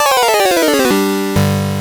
game_over.ogg